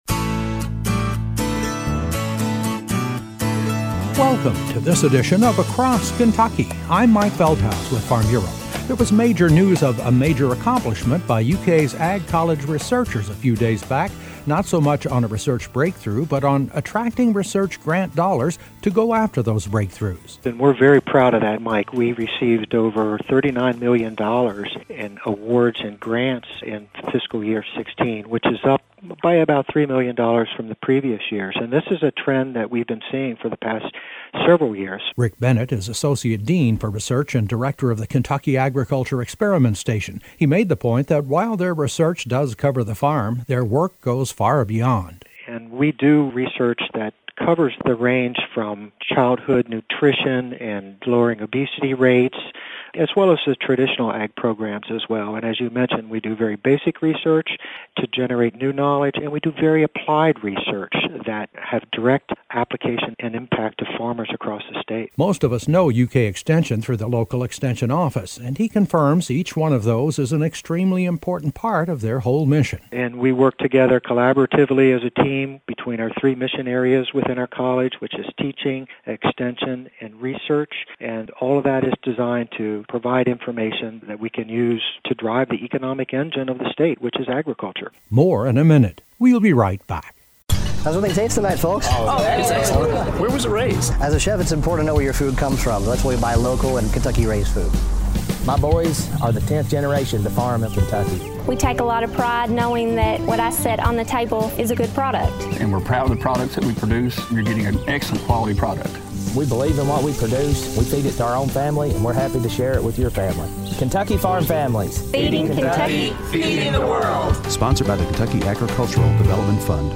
Across Kentucky - October 25, 2016: A report on the success in UKs ag research team in drawing over $39 million in grant funding last year.